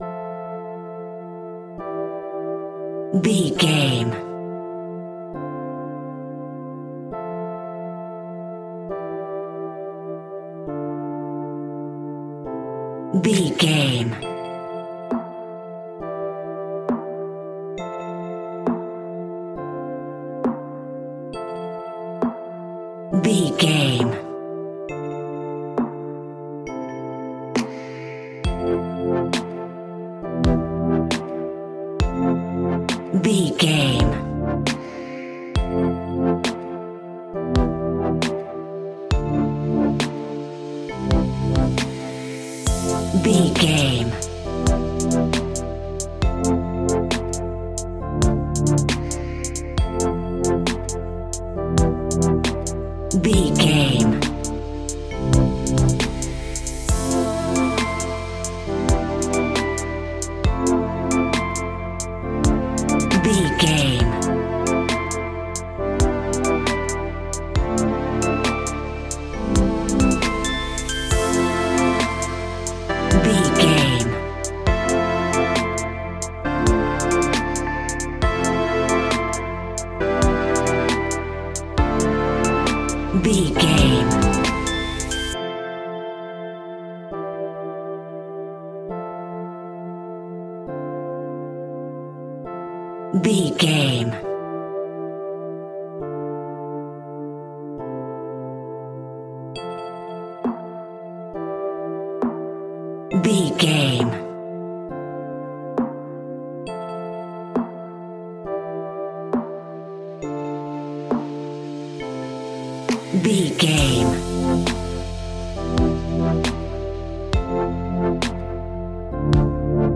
Aeolian/Minor
hip hop
instrumentals
laid back
groove
hip hop drums
hip hop synths
piano
hip hop pads